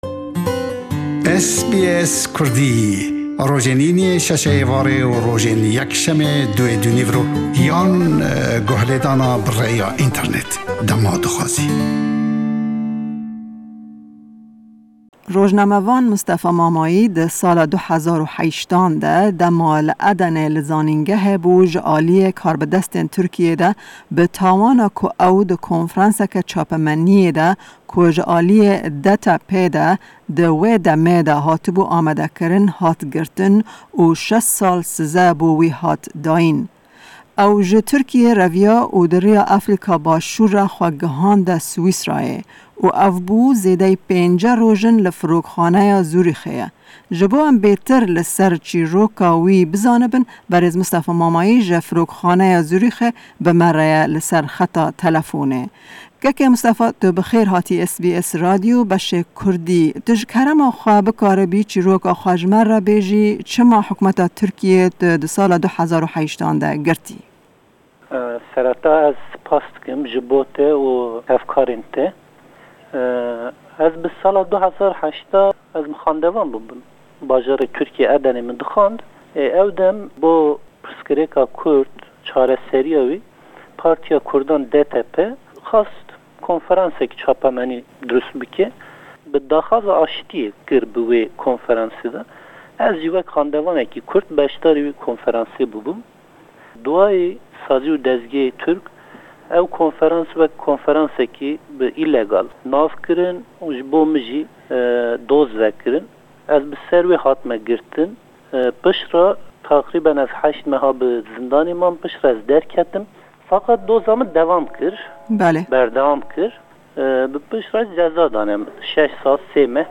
hevpeyvînek bi wî re pêk anî.